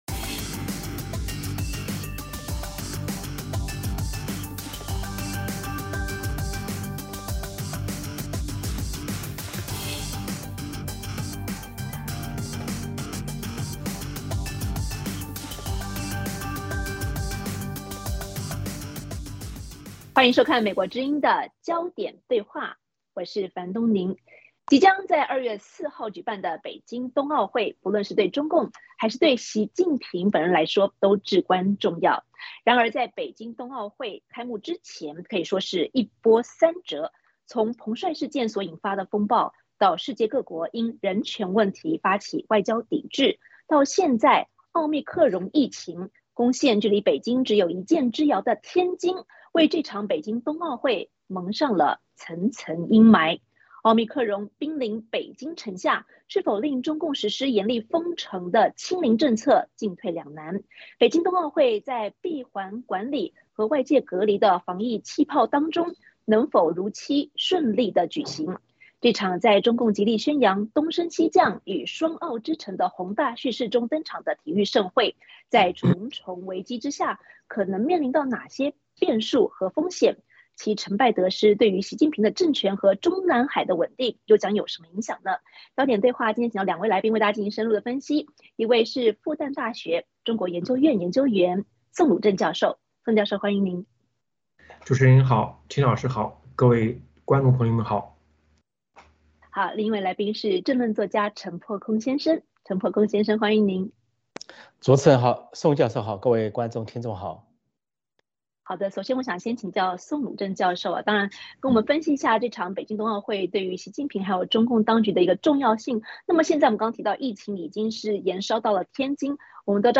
焦点对话请两位来宾交流不同观点。